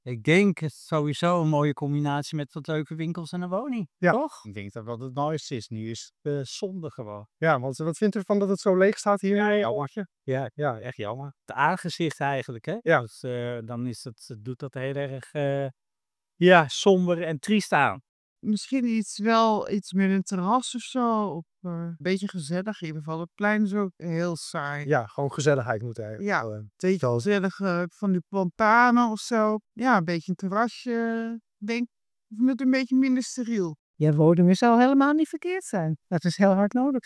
U luistert nu naar Passanten De Graaf Wichman in Huizen over de grote leegstand in het pand
passanten-de-graaf-wichman-in-huizen-over-de-grote-leegstand-in-het-pand.mp3